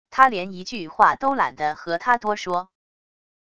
他连一句话都懒得和他多说wav音频生成系统WAV Audio Player